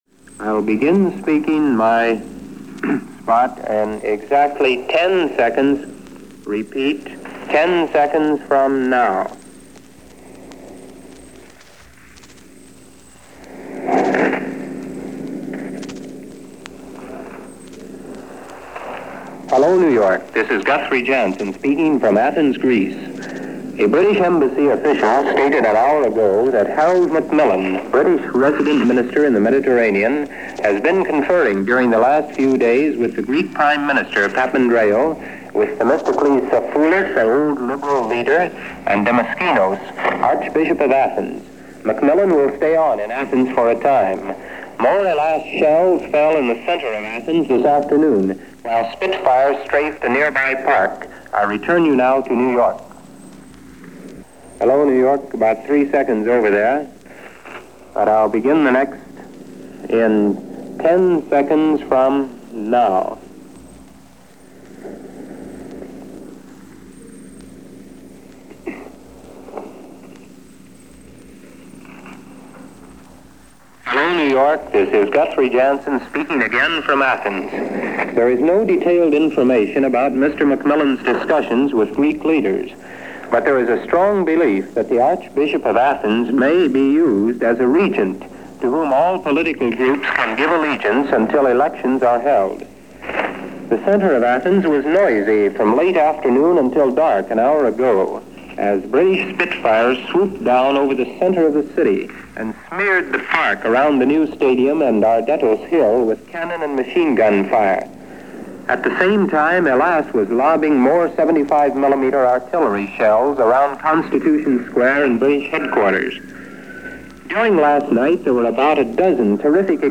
Here is a series of Shortwave reports, summing up the situation as it stood and what had transpired overnight.
Here are those reports from NBC News from December 15, 1944.